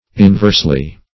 Inversely \In*verse"ly\, adv.